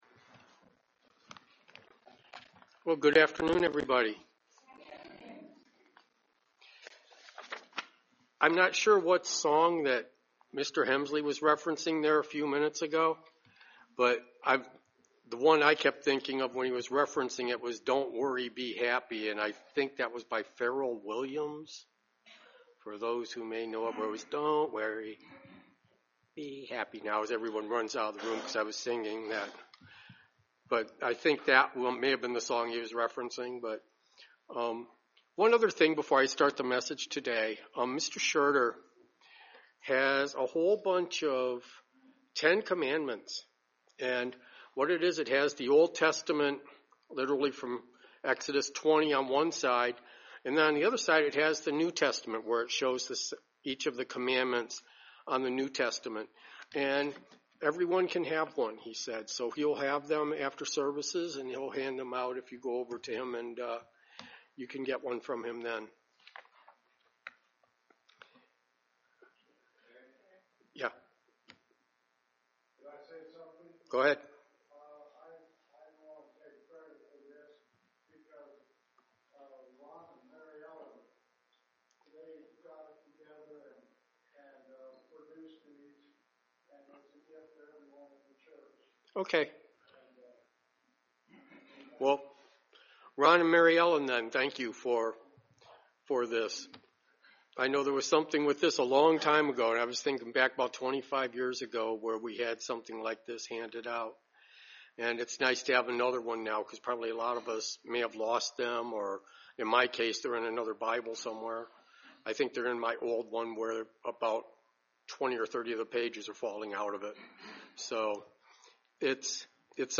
Given in Dayton, OH